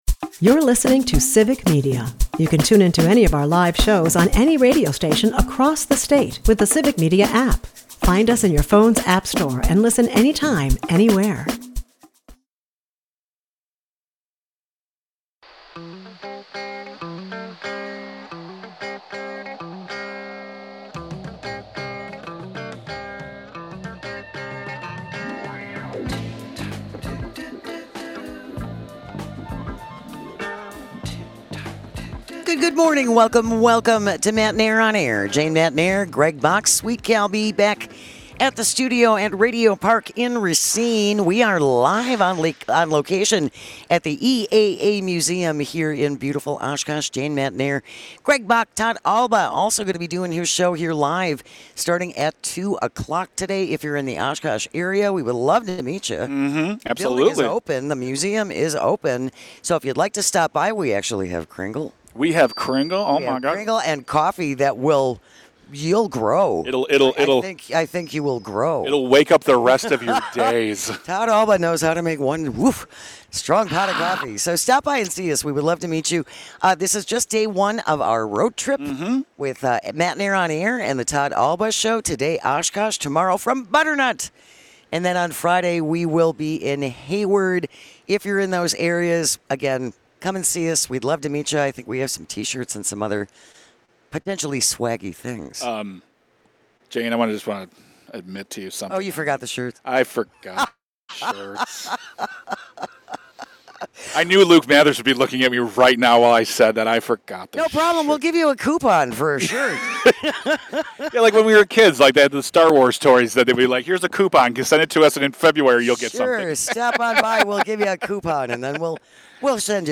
What better way to relax than listen to a handpicked selection of our favorite moment's from this week's show. This week we were live all over the state, so enjoy!